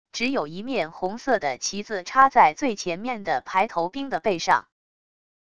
只有一面红色的旗子插在最前面的排头兵的背上wav音频生成系统WAV Audio Player